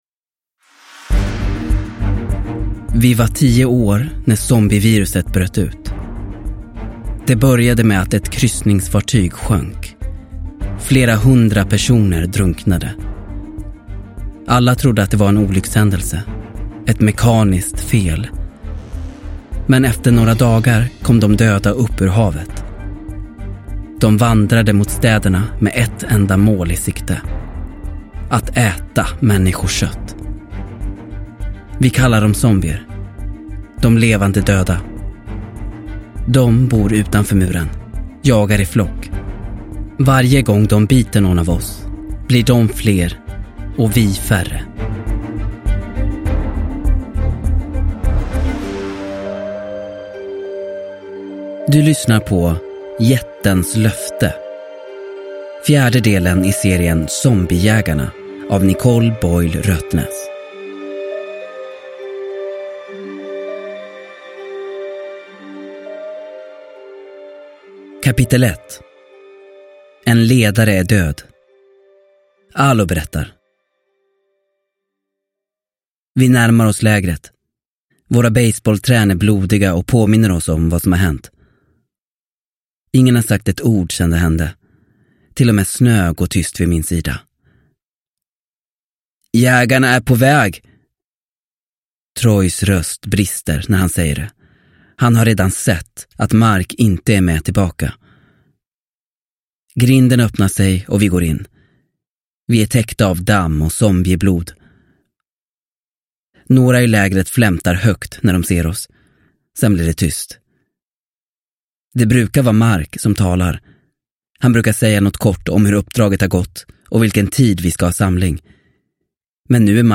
Jättens löfte – Ljudbok – Laddas ner